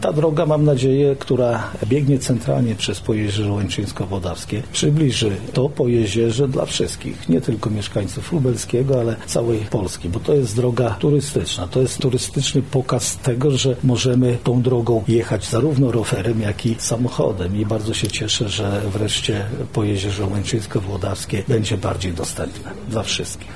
stawiaraletodroga – mówi Jarosław Stawiarski, Marszałek Województwa Lubelskiego